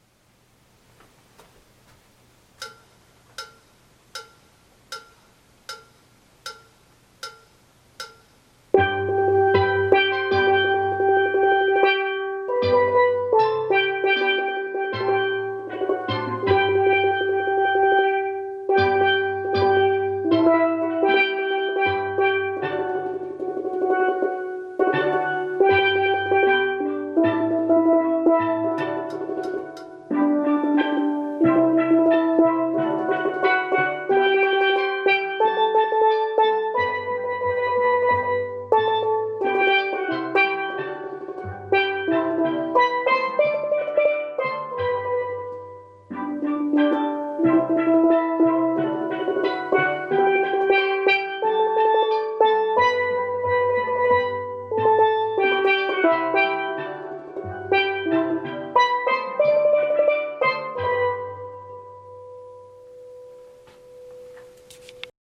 Ténor 2 Come Again Vid 78 90 .mp3